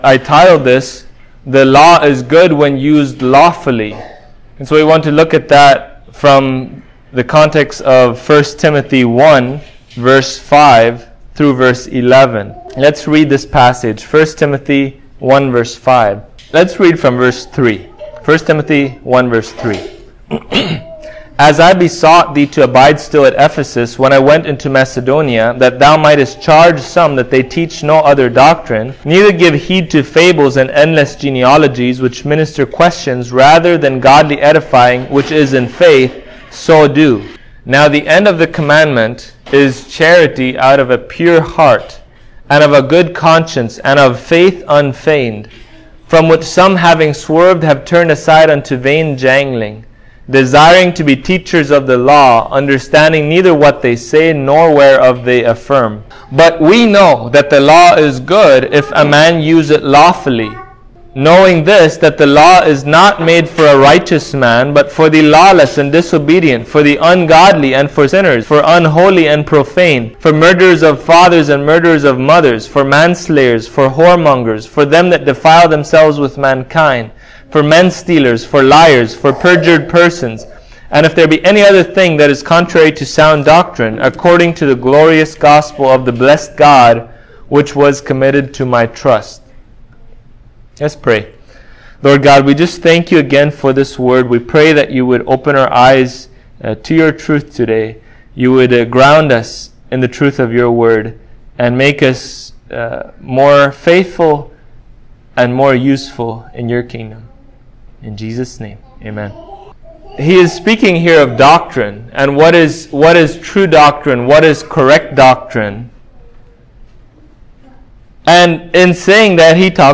1Timothy 1:5-11 Service Type: Sunday Morning How are we as Believers to use the law in a lawful way?